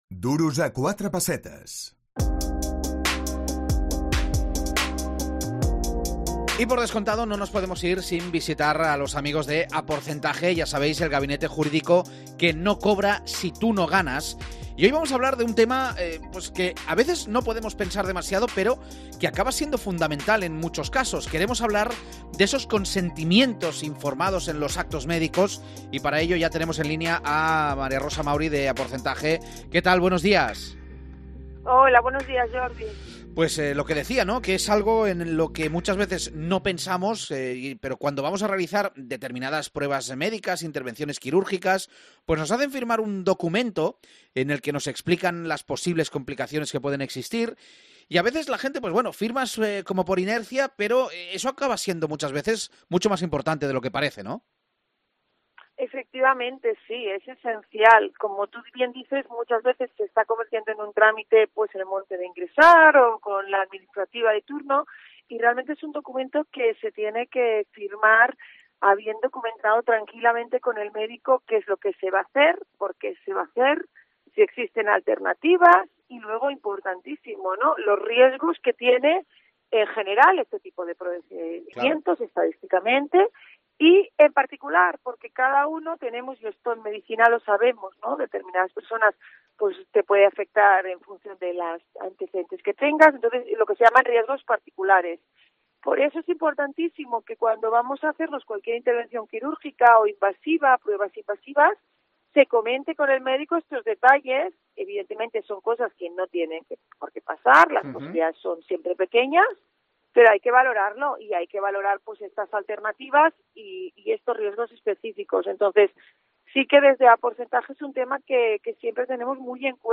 AUDIO: Hablamos del tema con los abogados de "Aporcentaje"